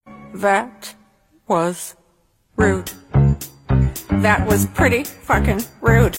that was rude Meme Sound Effect
that was rude.mp3